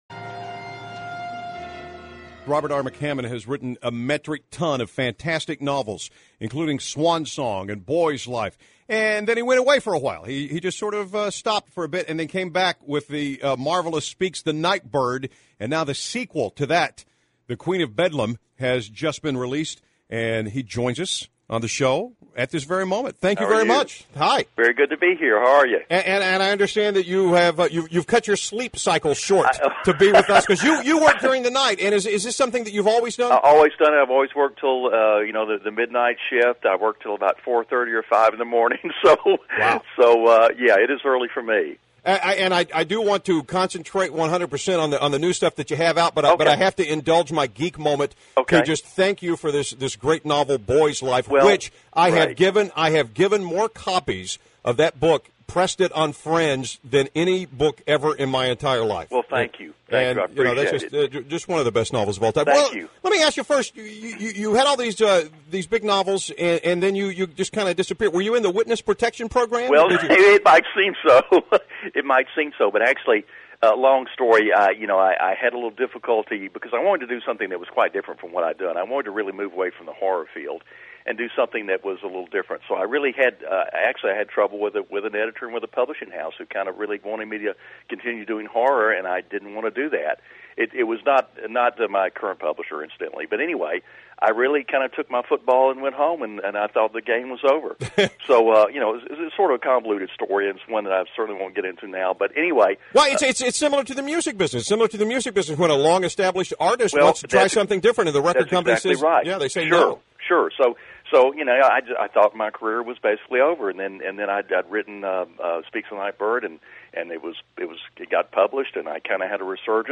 The link above is just the McCammon interview.